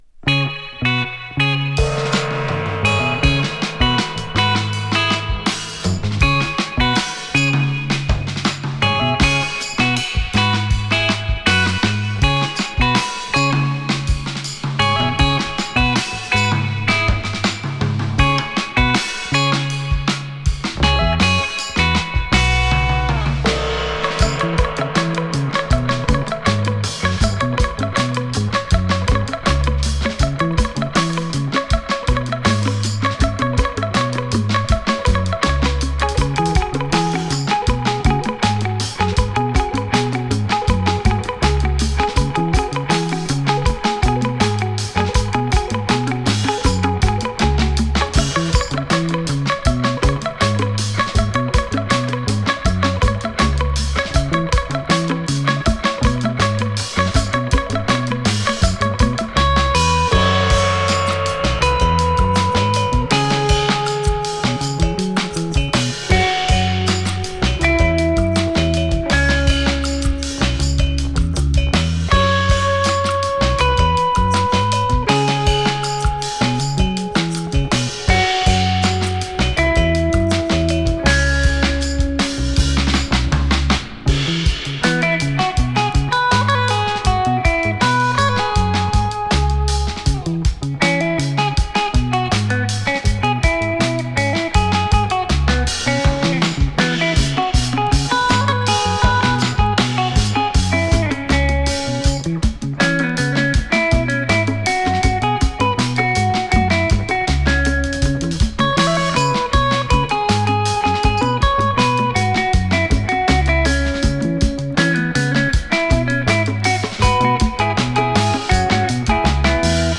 様々なリズム音楽の影響を受け、インストゥルメンタル・ミュージックに様々な音楽要素をミックス。